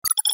جلوه های صوتی
دانلود صدای کلیک 40 از ساعد نیوز با لینک مستقیم و کیفیت بالا